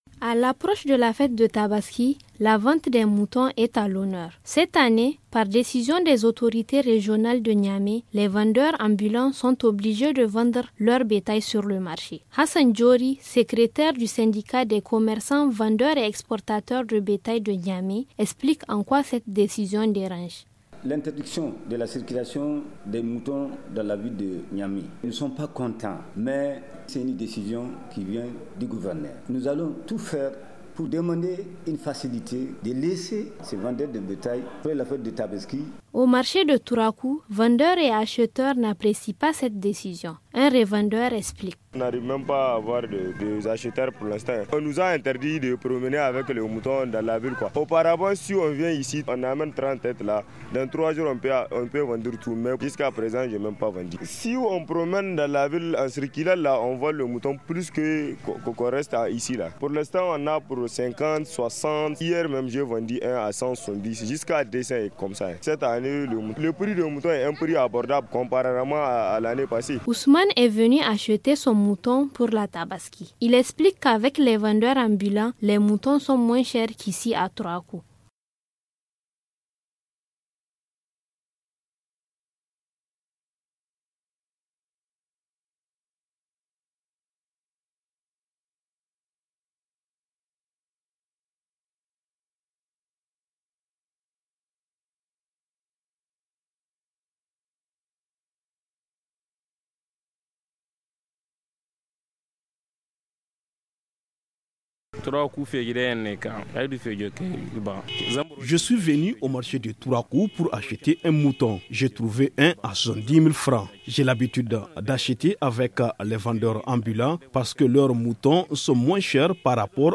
Reportage au marché de Tourakou